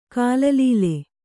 ♪ kālalīle